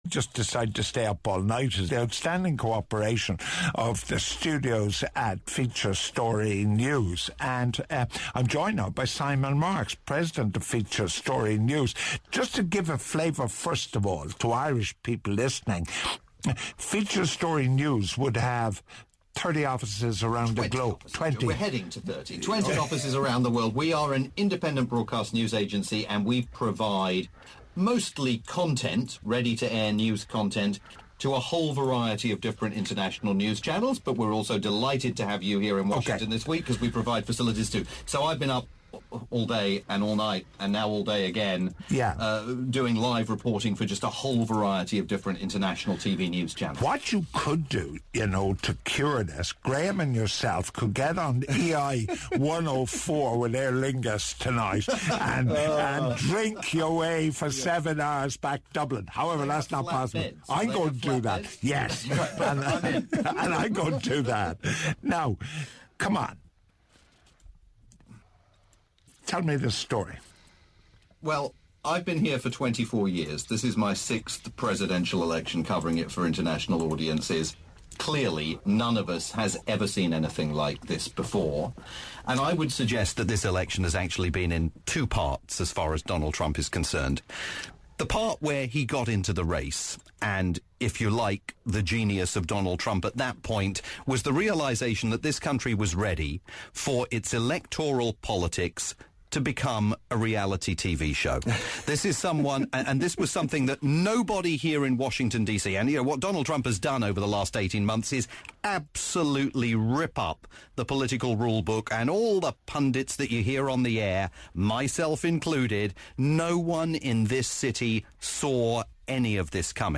a panel discussion about the outcome of the US election on Irish radio station Newstalk FM